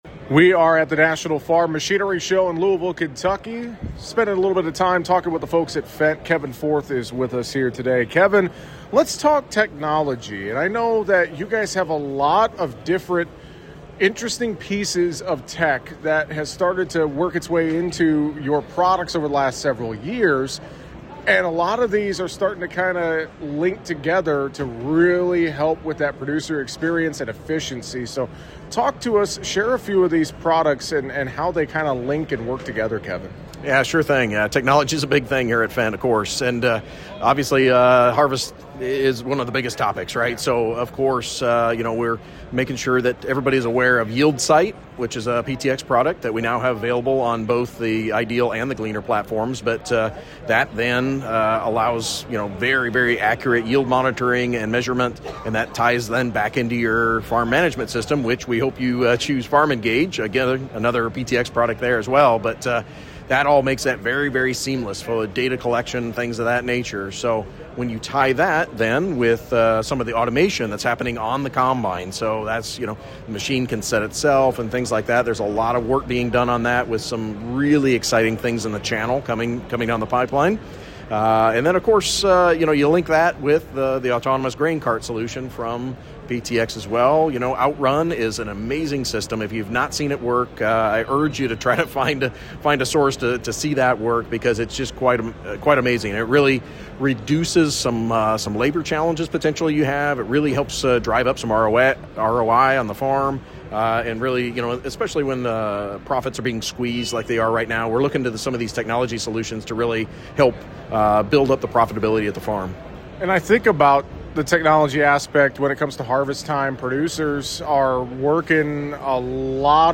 (LOUISVILLE, KY) — Technology in agriculture continues to not only advance but be a featured topic at farm shows around the country. While we spent time at the National Farm Machinery Show this week, we discuss some of the latest harvest technology being offered by Fendt.